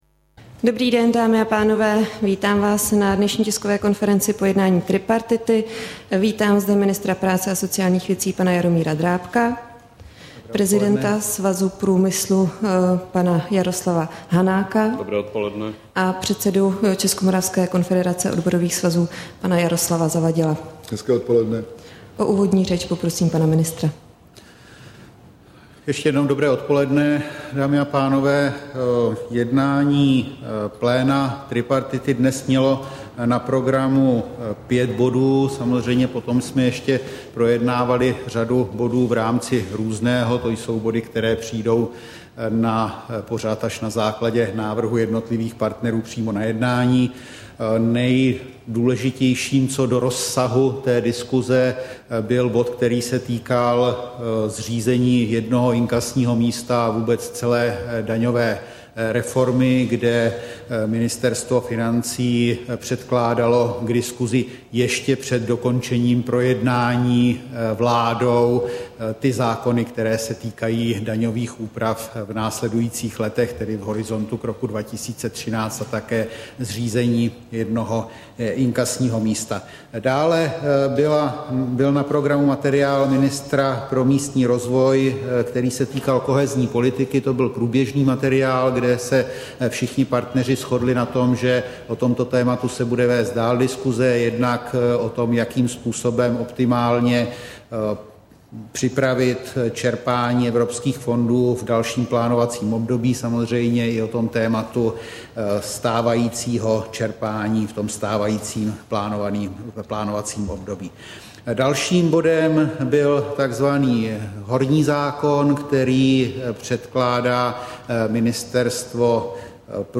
Tisková konference po jednání tripartity, 16. srpna 2011